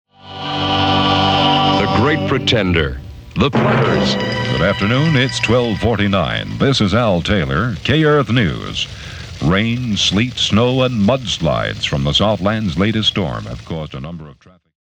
BondEn wie héél goed oplet hoort een bekend pingeltje dat ook de opmaat naar Veronica’s Popjournaal is.
Fragment-K-Earth-News-met-Popjournaal-pingel.mp3